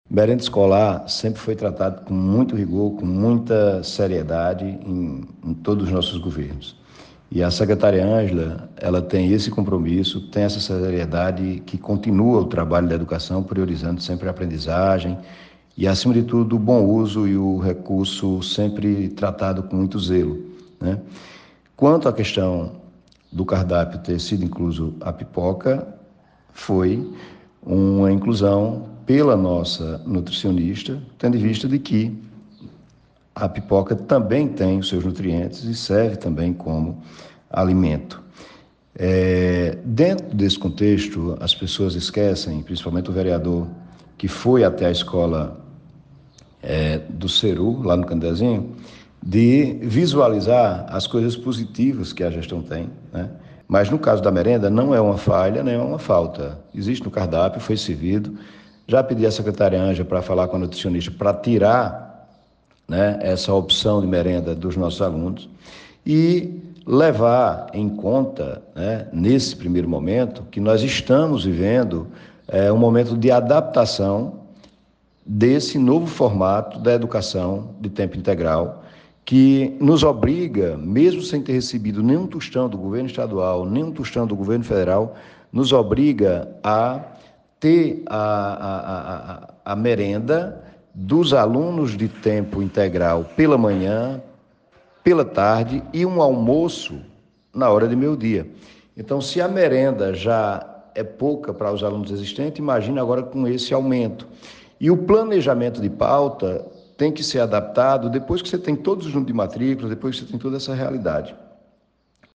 Em parte de áudio enviado para a Rádio Cultura, Zé Helder ainda falou de como é adquirida a merenda, mencionou a importância da pipoca e que mandou retirar esse item do cardápio.